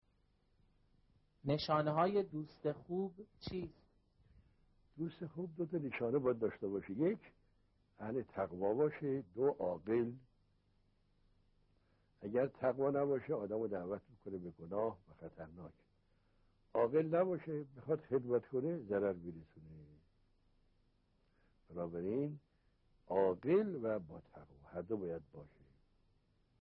درس اخلاق | چطور بفهمیم این رفیق به دردمان می‌خورد؟